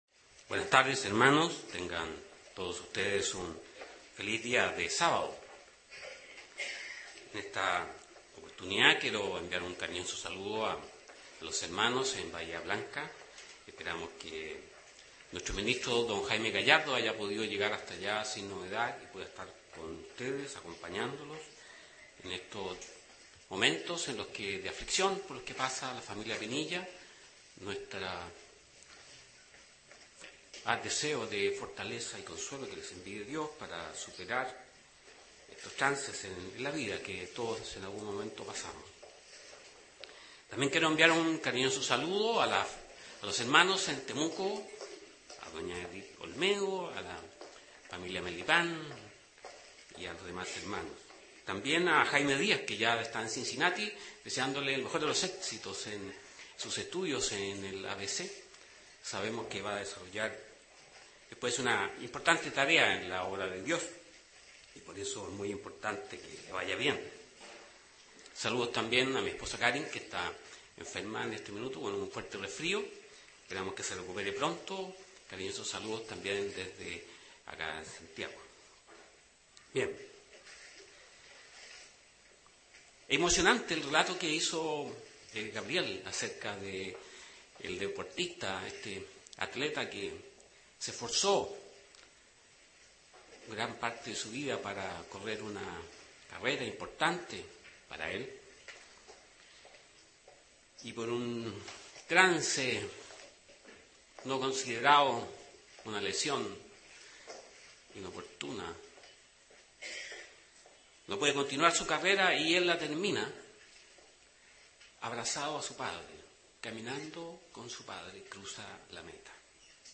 Given in Santiago